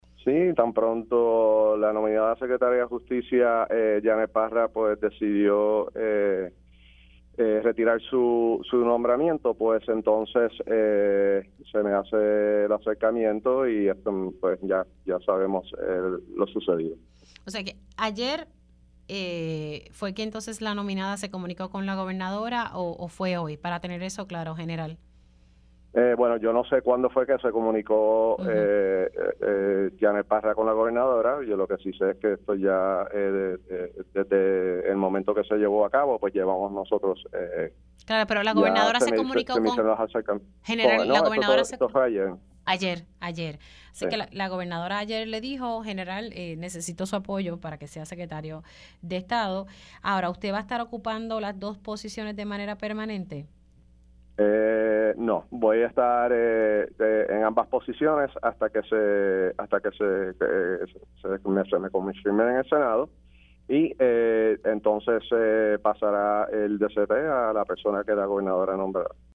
Voy a estar en ambas posiciones hasta que se me confirme en el Senado y, entonces, se pasará el DSP a la persona que la gobernadora nombrará“, indicó en entrevista para Pega’os en la Mañana al mostrarse confiado en que tendrá éxito en su gobernación, tras salir airoso la primera vez en el Senado.